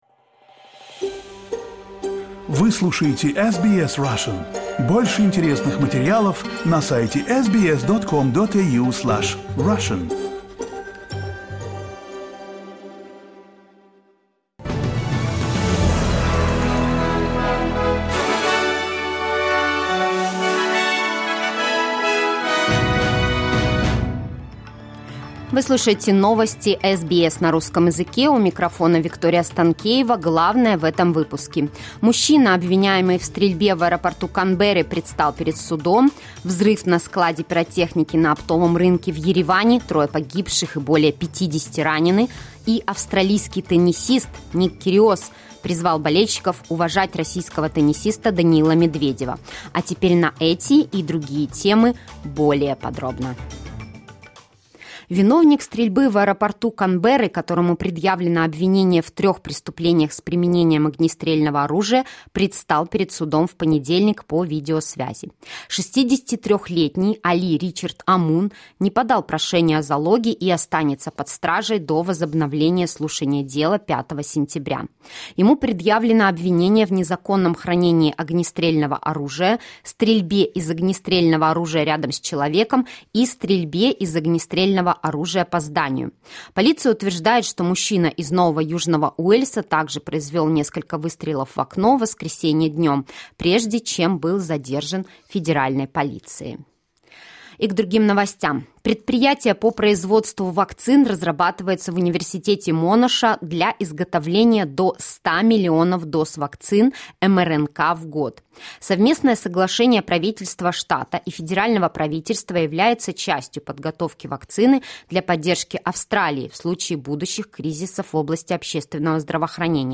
SBS News in Russian - 15.08.2022